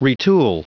Prononciation du mot retool en anglais (fichier audio)
retool.wav